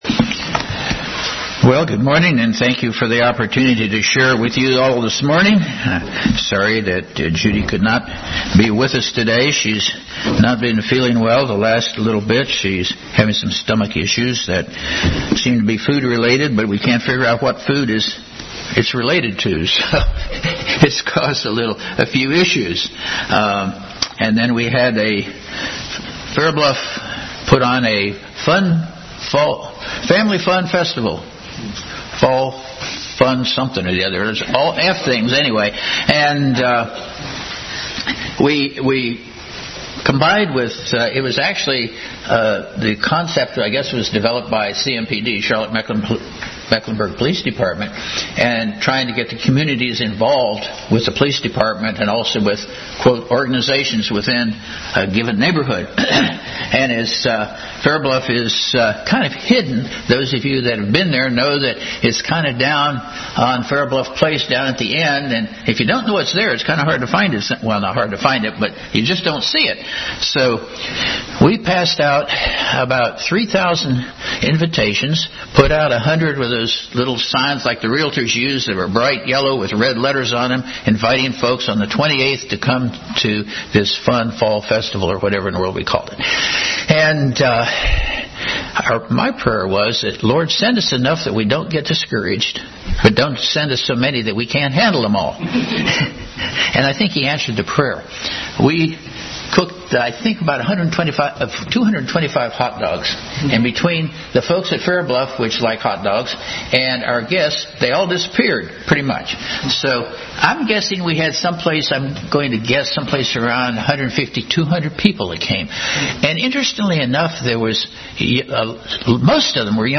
Bible Text: Romans 12:1-2, Romans 5:1, 8:10-11, 1Corinthians 6:20, Genesis 4:6-7, Leviticus 1:10, Deuteronomy 15:21, Galatians 2:20 | Adult Sunday School continued study in the book of Romans.